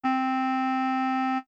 clarinette-Do3.wav